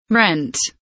rent kelimesinin anlamı, resimli anlatımı ve sesli okunuşu